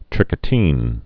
(trĭkə-tēn, trēkə-)